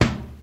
Crisp Bass Drum Single Shot C Key 717.wav
Royality free kickdrum sample tuned to the C note. Loudest frequency: 1344Hz
crisp-bass-drum-single-shot-c-key-717-yS5.mp3